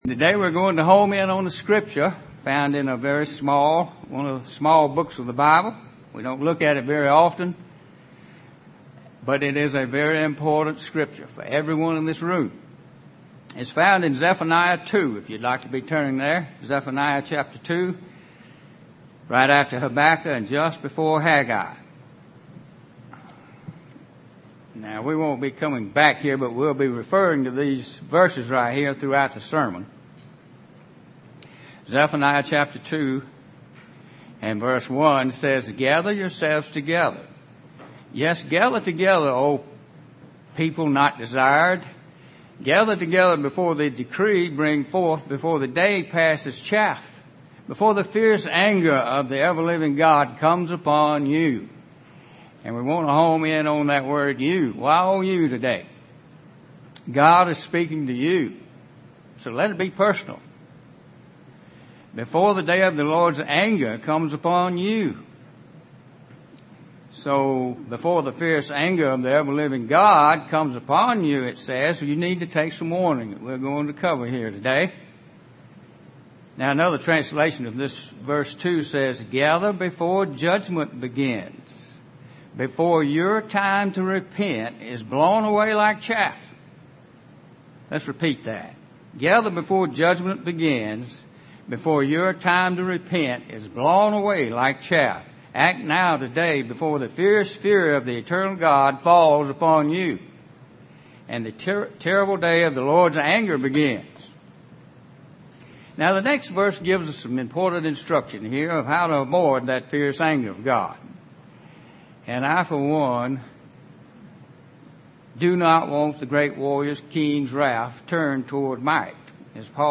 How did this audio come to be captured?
Given in Columbus, GA